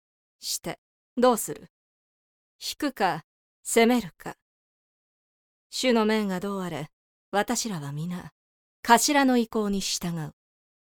【サンプルセリフ】
ゆったりした大人の女性が良いなと思って設定させていただきました。